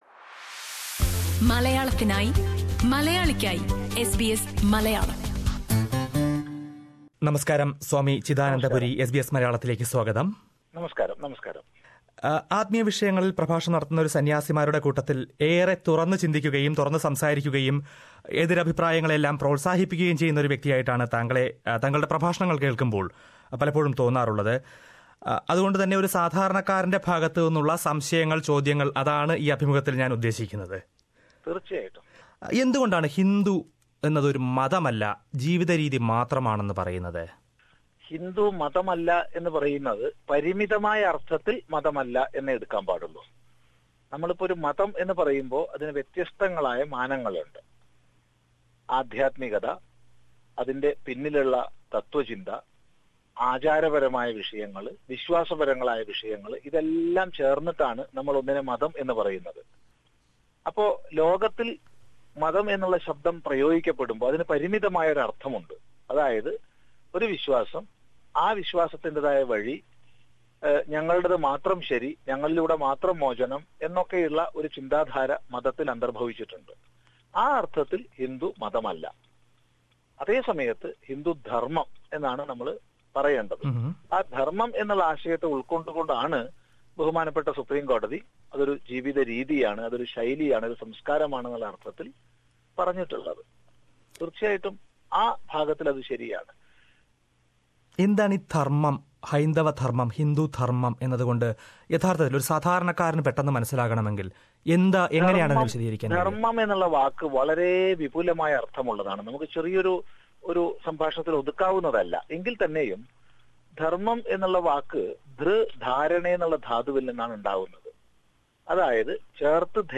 അഭിമുഖം